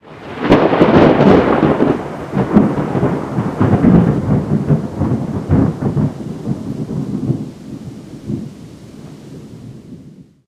Звук продолжительного грождяма и дождя.